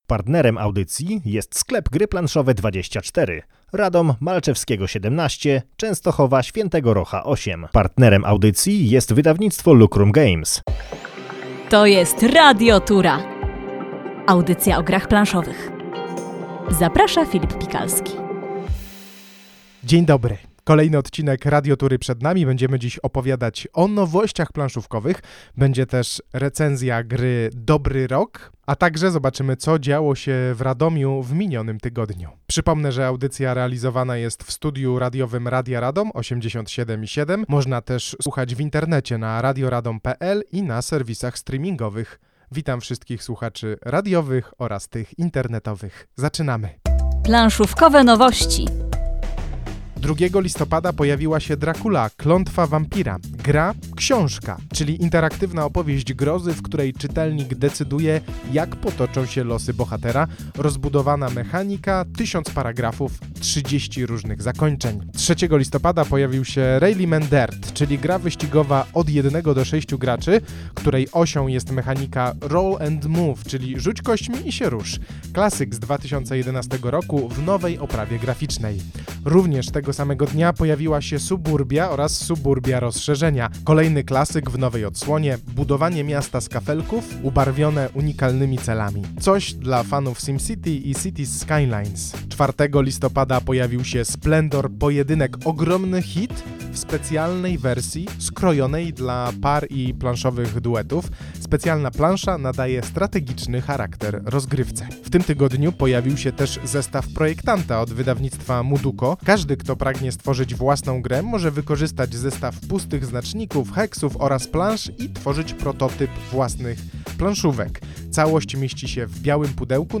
Audycja o grach planszowych w Radiu Radom na 87,7 FM!